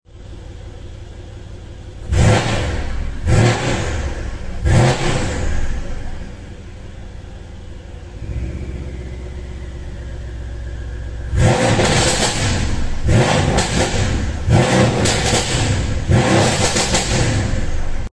Stage 2 W205 C63S